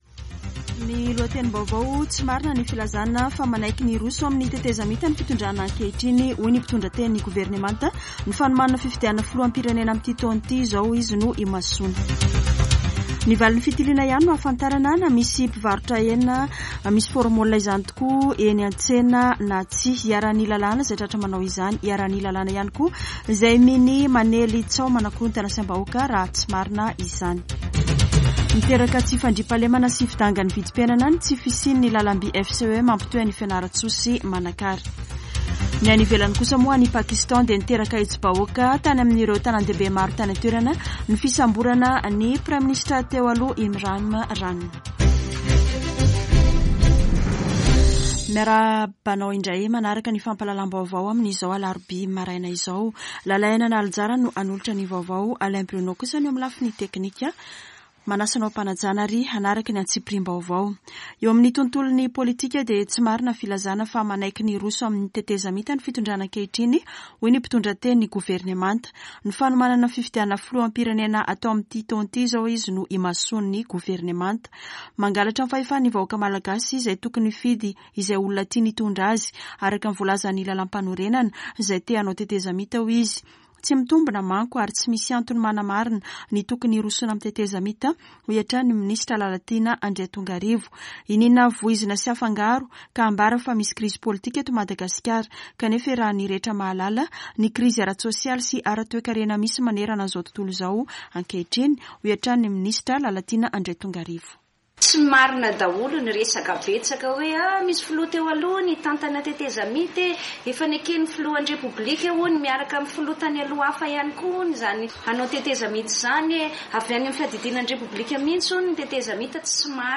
[Vaovao maraina] Alarobia 10 mey 2023